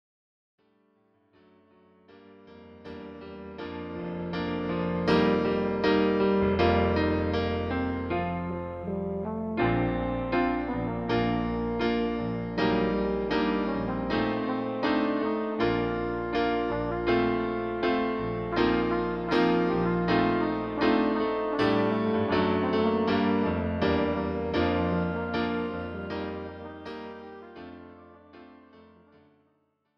This is an instrumental backing track cover.
• Key –  B♭, C
• With Backing Vocals
• No Fade